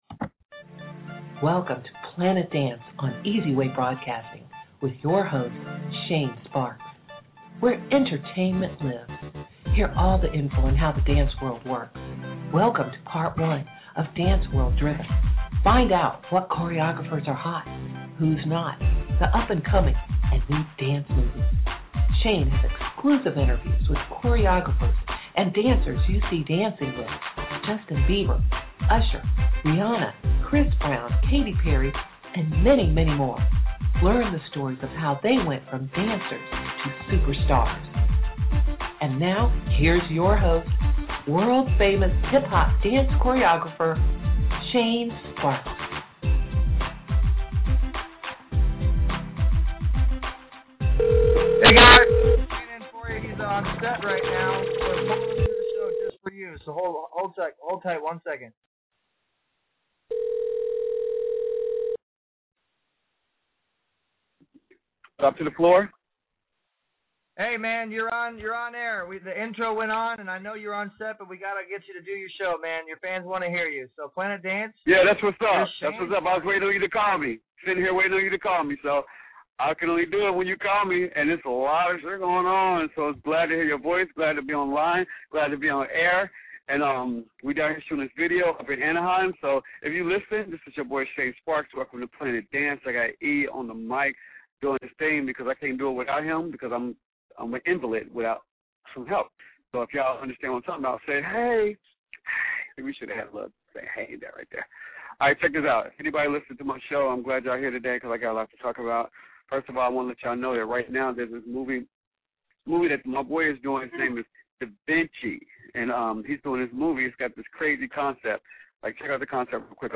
Hip Hop Music